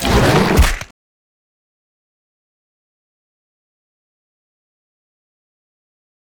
vpunch1.ogg